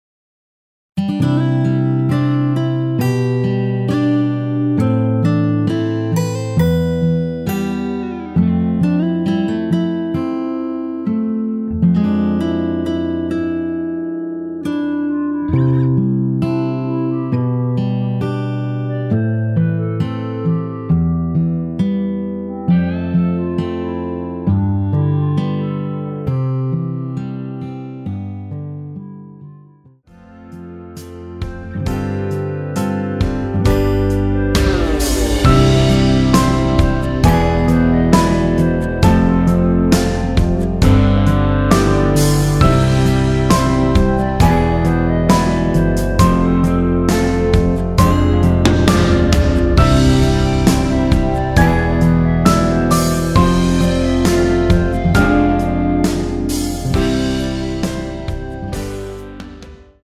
원키 멜로디 포함된 MR 입니다.
앞부분30초, 뒷부분30초씩 편집해서 올려 드리고 있습니다.